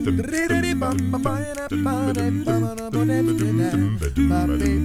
ACCAPELLA 1C.wav